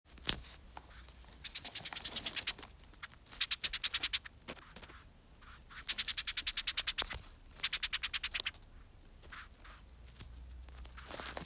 If you do not know what the calls a possum joey makes to its mother sounds like – please click the buttons below to hear the specific species audio recordings.
Western Pygmy Chattering
Pygmy-chattering.m4a